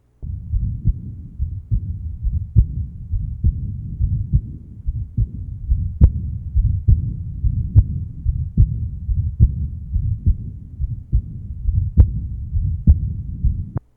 Date 1969 Type Diastolic Abnormality Rheumatic Heart Disease 14 year old with rheumatic aortic insufficiency. Esxcellent S3 and EDM [end diastolic murmur] To listen, click on the link below.